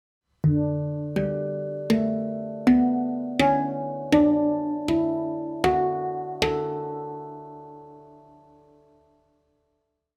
Moon II Handpan D-dur
Den gir en klar, lys klang og lang sustain, perfekt for meditative og stemningsfulle musikkopplevelser.
• Stemt i D-dur for en lys og harmonisk lyd.
• Lang sustain, rask toneutvikling og meditative kvaliteter.
D3, G3, A3, B3, C#4, D4, E4, F#4, A4